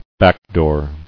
[back·door]